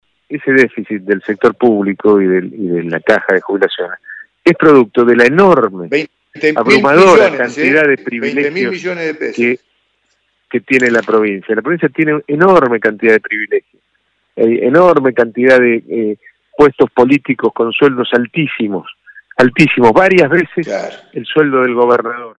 el ex ministro del Interior, Rogelio Frigerio, habló de la actualidad provincial:
Declaraciones-de-Rogelio-Frigerio-2.mp3